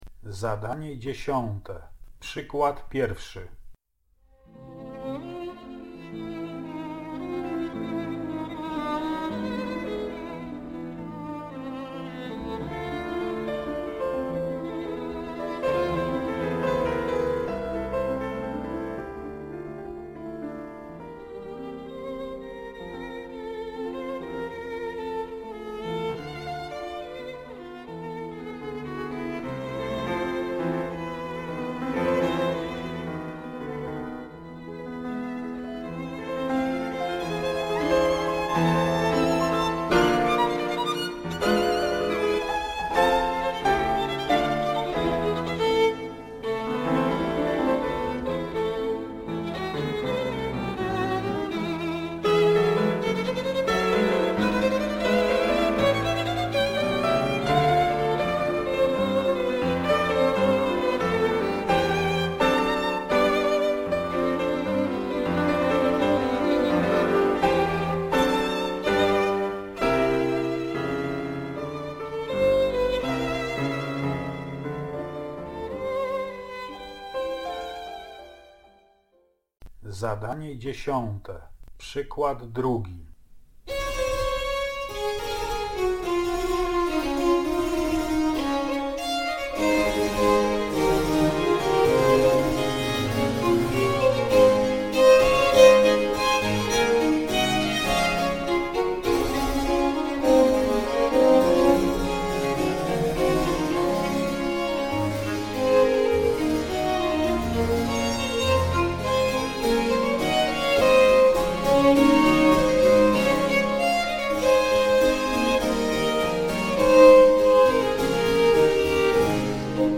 Zapoznaj się z nagraniami fragmentów sonat skrzypcowych pochodzących z trzech różnych epok (przykład 1. – ścieżka 4., przykład 2. – ścieżka 5., przykład 3. – ścieżka 6.).